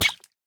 Minecraft Version Minecraft Version snapshot Latest Release | Latest Snapshot snapshot / assets / minecraft / sounds / mob / axolotl / hurt3.ogg Compare With Compare With Latest Release | Latest Snapshot
hurt3.ogg